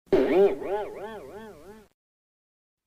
PLAY vv_spring cartoon 2
vv-spring-cartoon.mp3